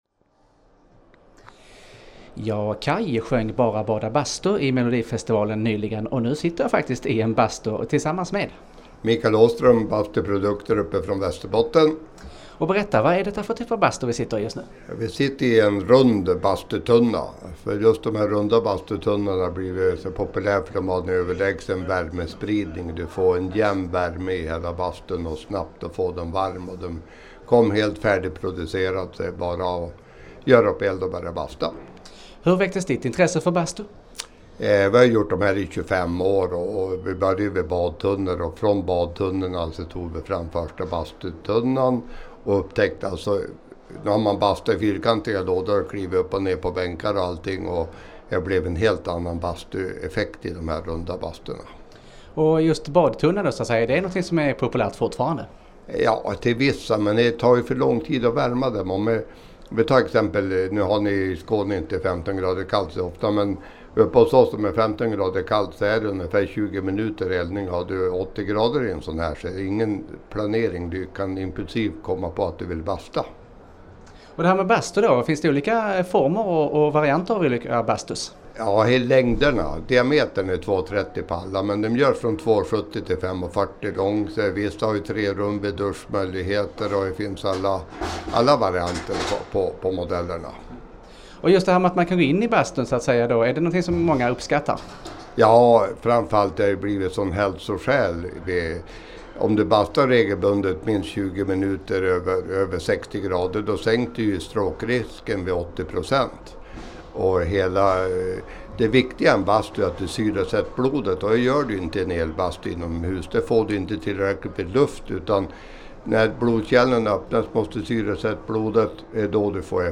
Intervjun sändes i Malmö Direkt den 10 mars och kan nu höras här.